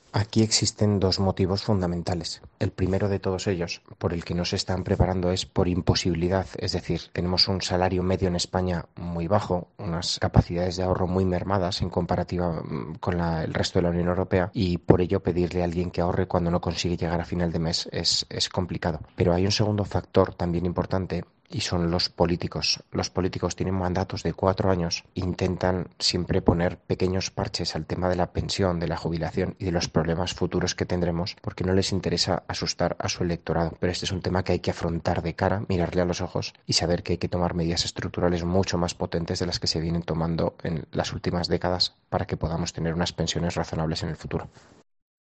experto financiero, nos explica por qué no conseguimos ahorrar